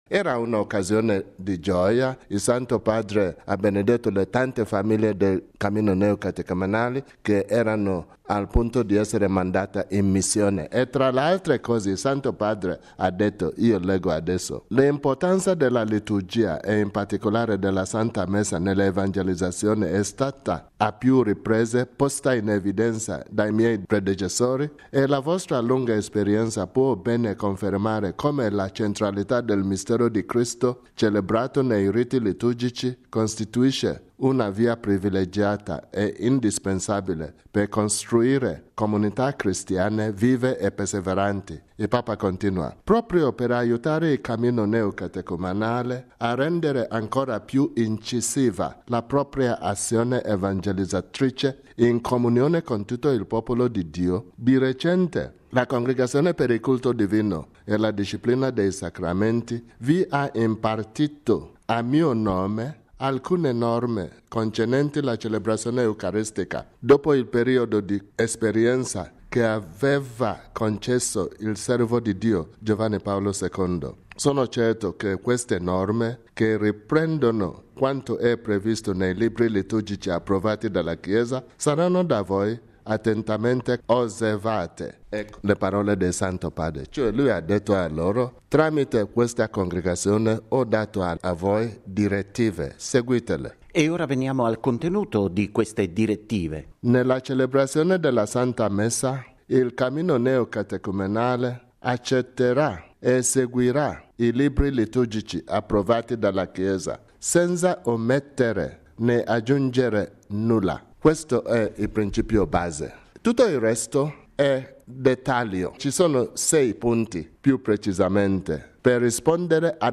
Neocatecumenali e liturgia: intervista con il card. Arinze